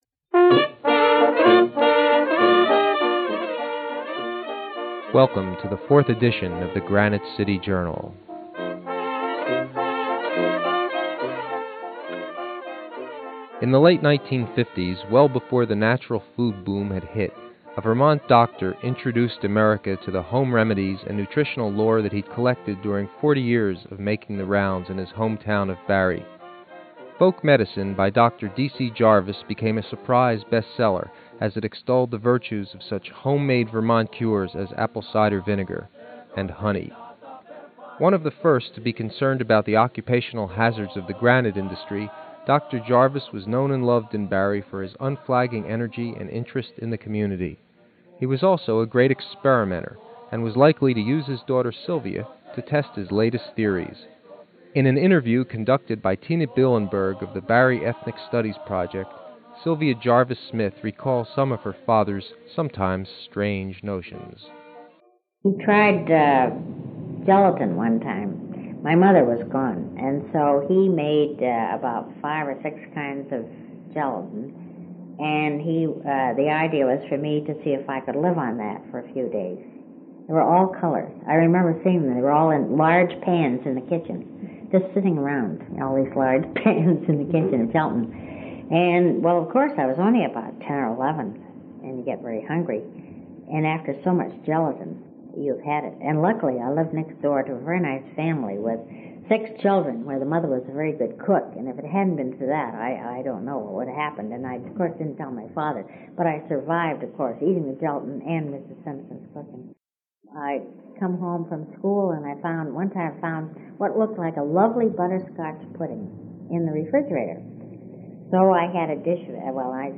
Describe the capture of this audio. As a result, the audio quality of the interviews varies.